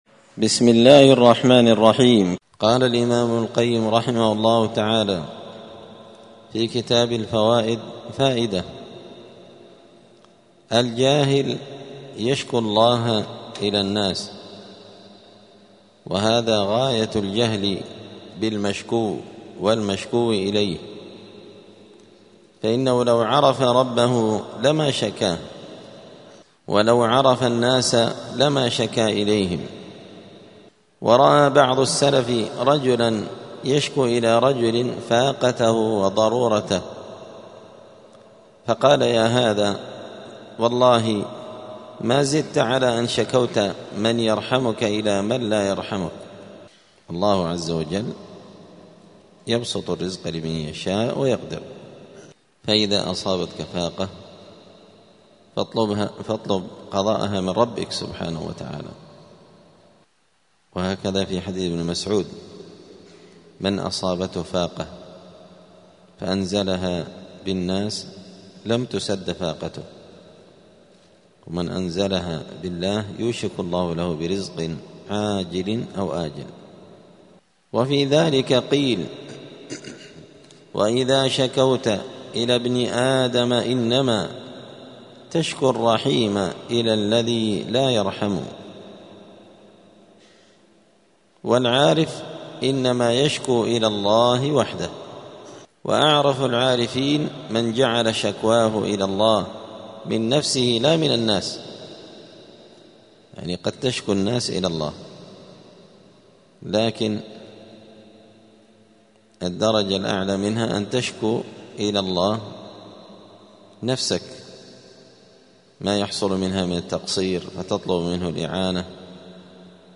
*الدرس الثامن والأربعون (48) {فصل: الجاهل يشكو الله إلى الناس}*